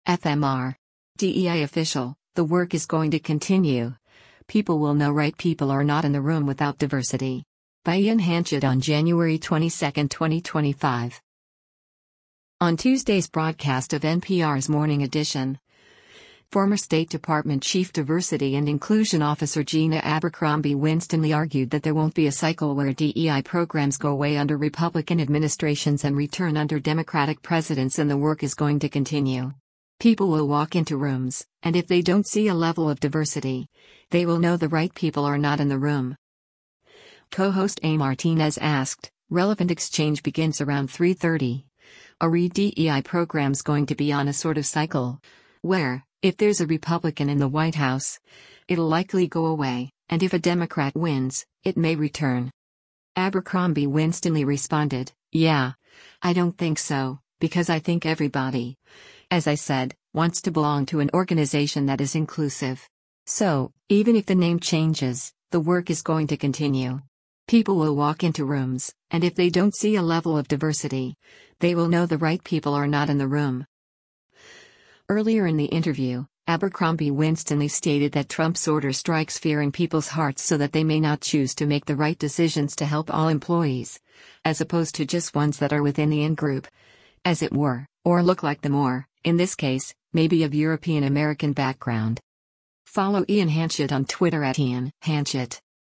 On Tuesday’s broadcast of NPR’s “Morning Edition,” former State Department Chief Diversity and Inclusion Officer Gina Abercrombie-Winstanley argued that there won’t be a cycle where DEI programs go away under Republican administrations and return under Democratic presidents and “the work is going to continue. People will walk into rooms, and if they don’t see a level of diversity, they will know the right people are not in the room.”
Co-host A Martínez asked, [relevant exchange begins around 3:30] “[A]re DEI program’s going to be on a sort of cycle, where, if there’s a Republican in the White House, it’ll likely go away, and if a Democrat wins, it may return?”